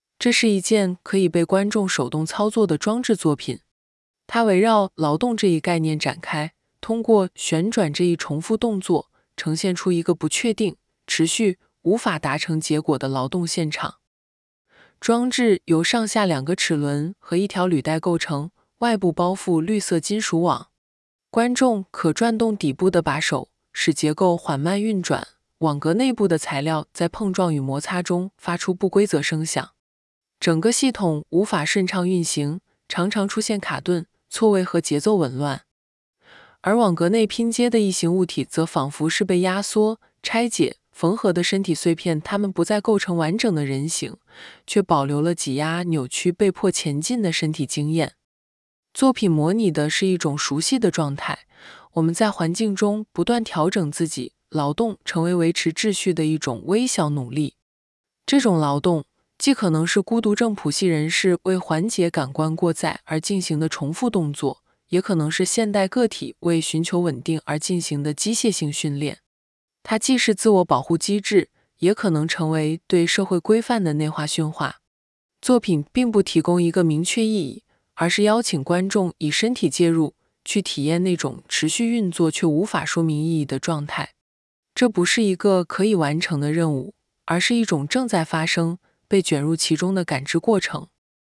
观众可转动底部的把手，使结构缓慢运转，网格内部的材料在碰撞与摩擦中发出不规则声响。整个系统无法顺畅运行，常常出现卡顿、错位和节奏紊乱。